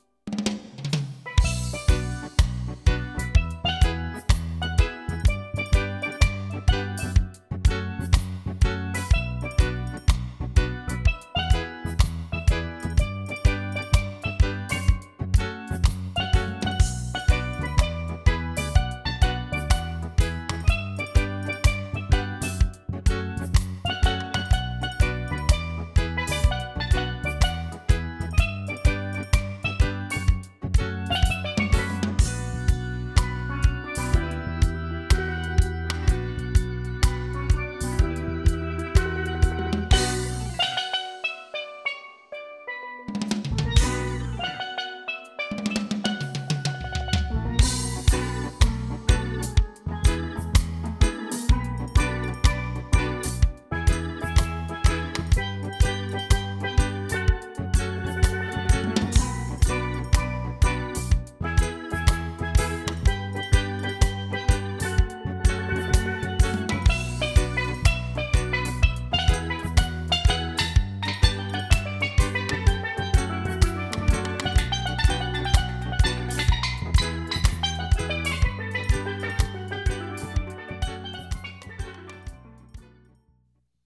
A sample of the Caribbean music we play.
Reggae